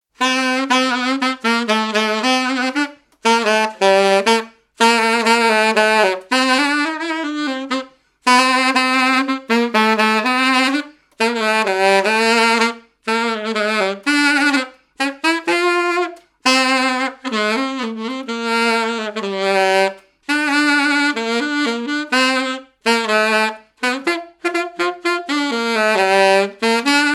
danse : marche
Genre strophique
activités et répertoire d'un musicien de noces et de bals
Pièce musicale inédite